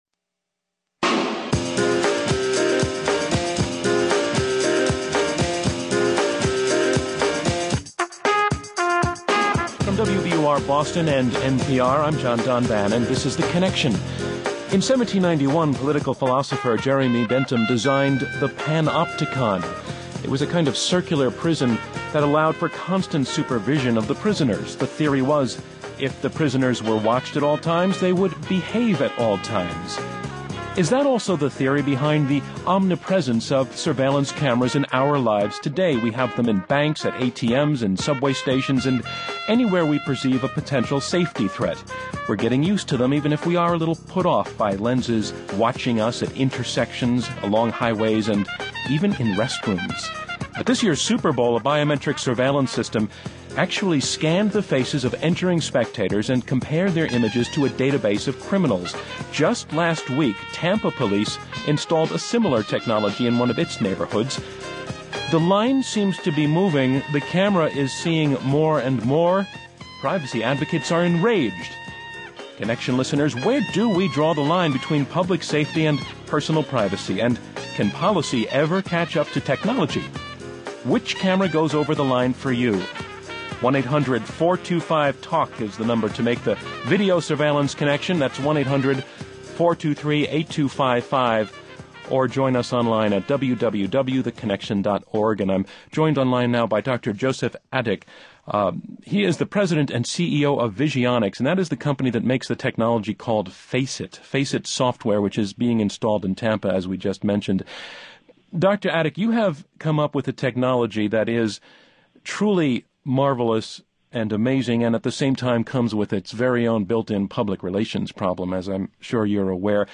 (Hosted by John Donvan)